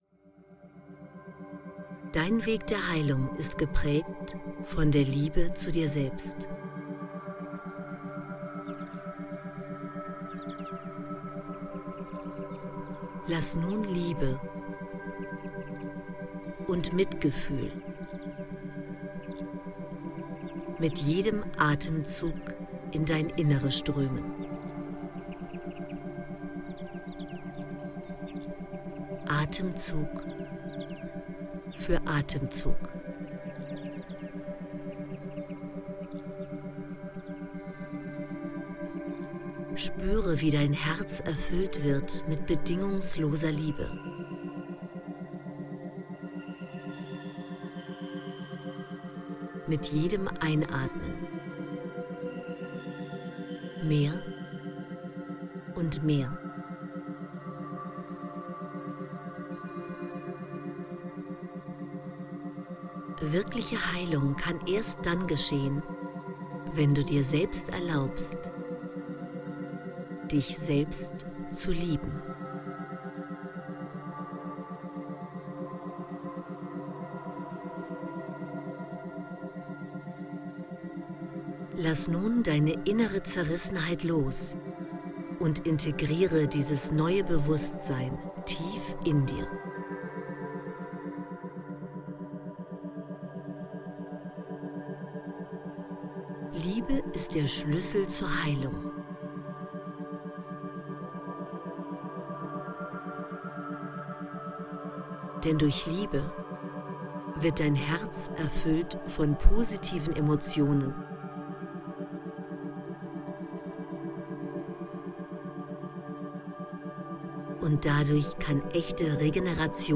Schmerzlinderung & Gesundheit durch emotionale Heilung & Begegnung mit dem inneren Arzt – Dieses Hörbuch aktiviert Ihre Selbstheilungskräfte und hilft Ihnen dabei Ihre mentale Kraft optimal auszuschöpfen, um körperlich und seelisch gesund zu sein.
Die geführte Mentalanwendung (Titel 2) begleitet Ihren ganzheitlichen Heilungsprozess.